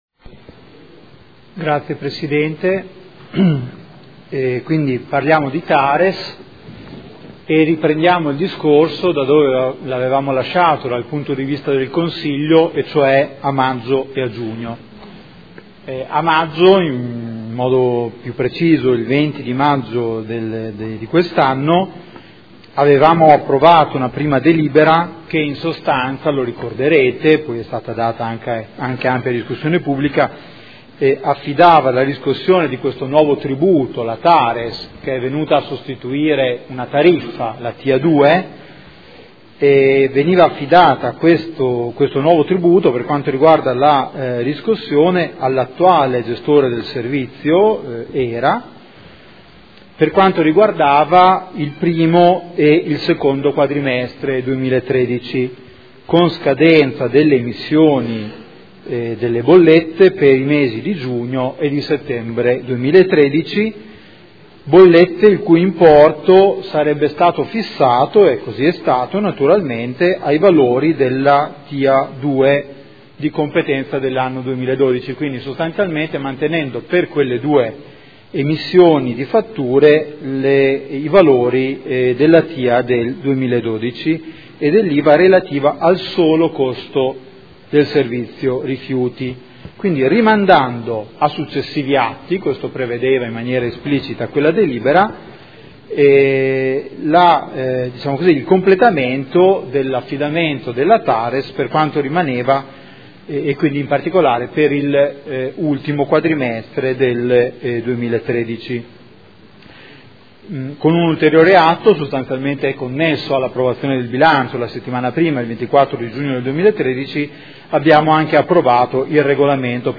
Giuseppe Boschini — Sito Audio Consiglio Comunale
Seduta del 10/10/2013 Proposta di deliberazione Convenzione per l’affidamento della gestione della riscossione del tributo comunale sui rifiuti e sui servizi indivisibili – Tares – Anno 2013